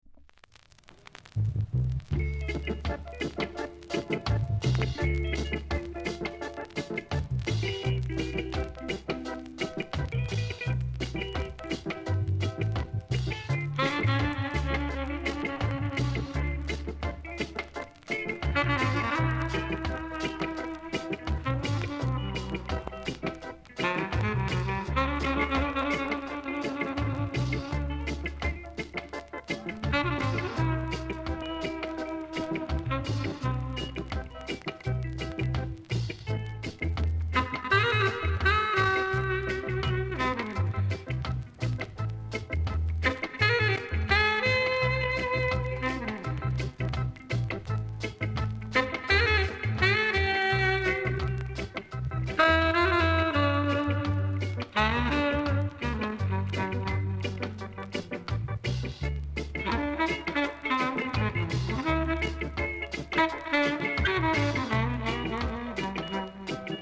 ※出だしでややチリチリします。ほかチリ、パチノイズが少しあります。
コメント MELLOW INST!!